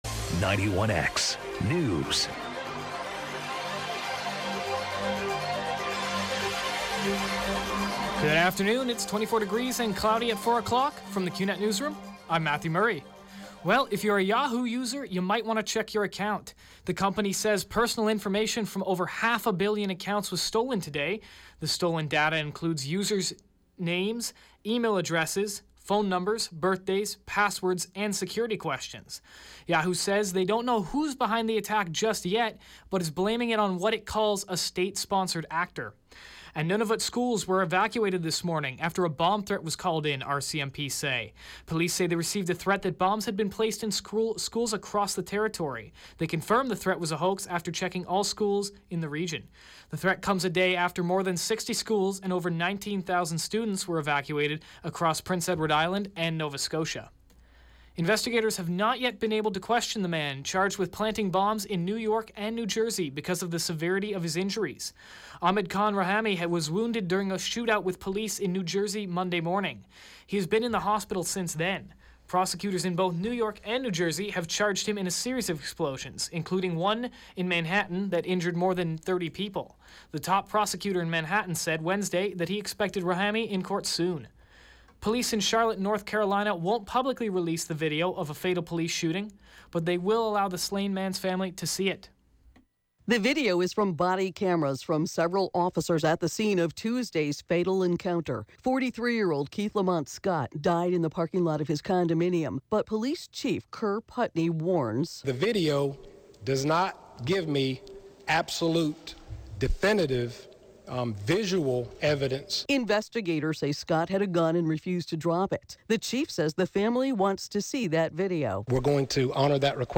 91X Newscast – Thursday, Sept. 22nd, 2016, 4 p.m.